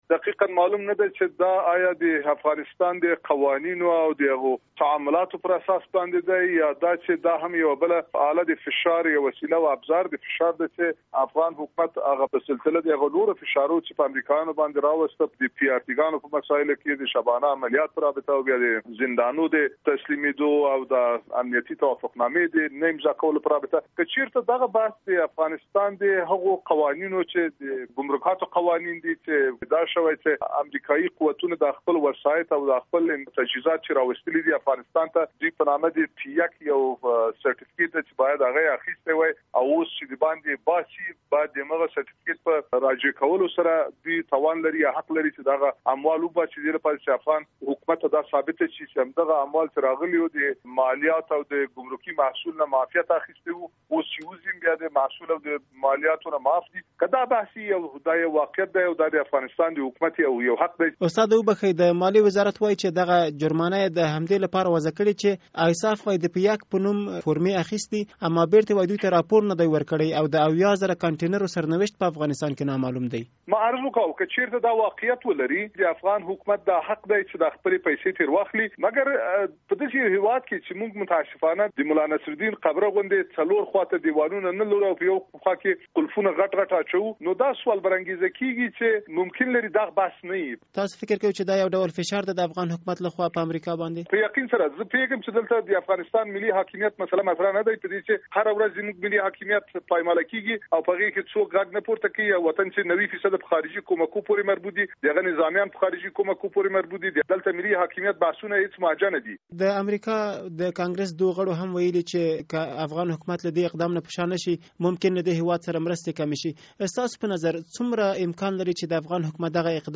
له حمیدالله فاروقي سره مرکه